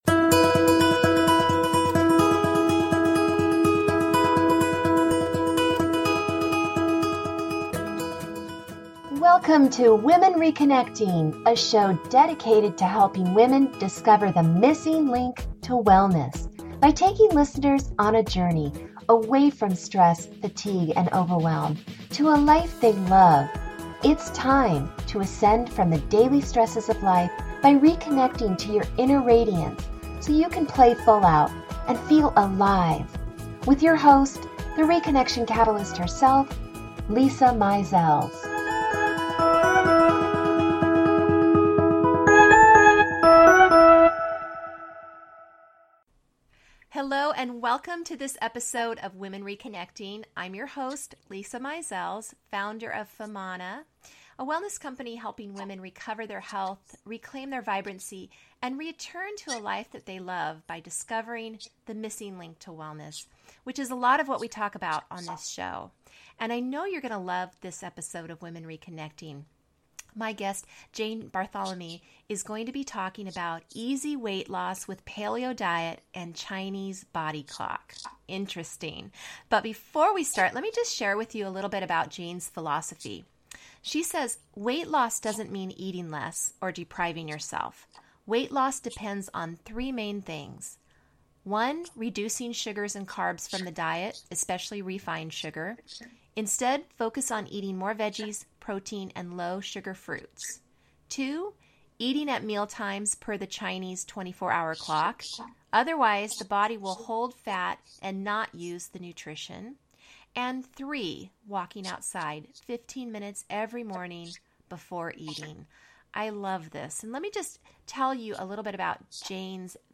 Talk Show Episode, Audio Podcast